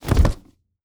Foley Sports / Football - Rugby / Scrum B.wav